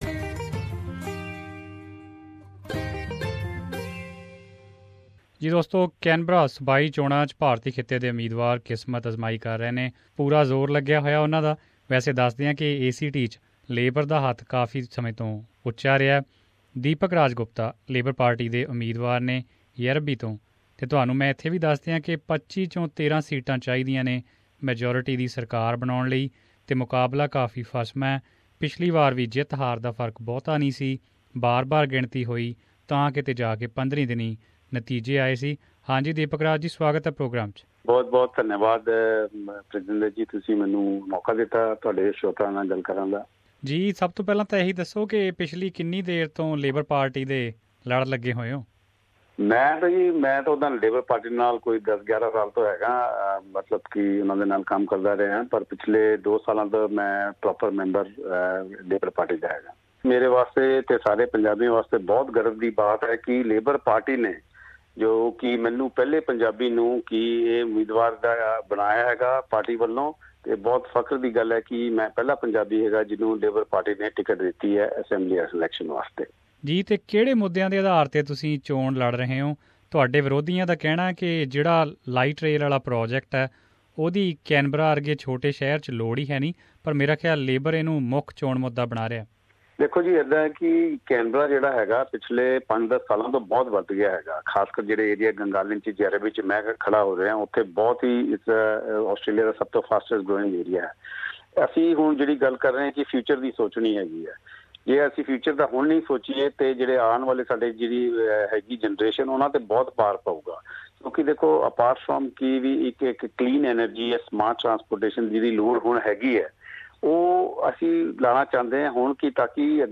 Deepak-Raj Gupta is an Indian Australian politician who has been living in Gungahlin for the past 14 years with his family. Here he is in conversation